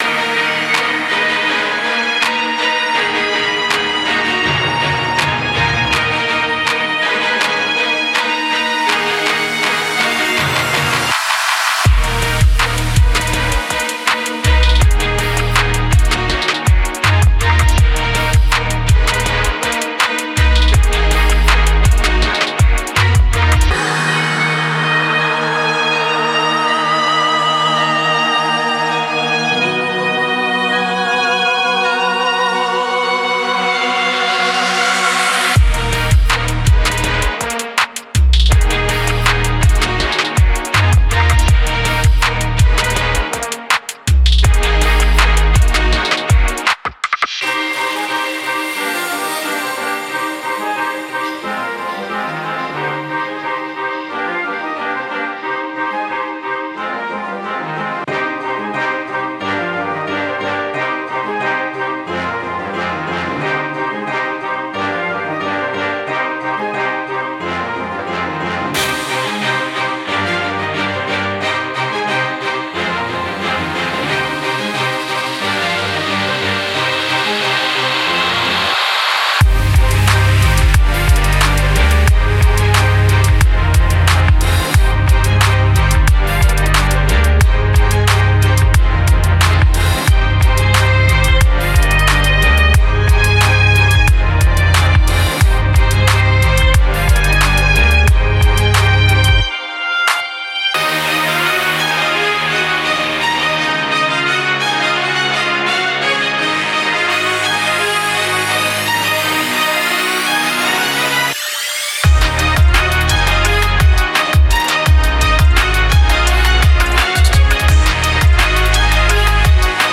Genre:Hip Hop
オーケストラル・ヒップホップ――シネマティックな力強さとストリートの荒々しさが融合。
デモサウンドはコチラ↓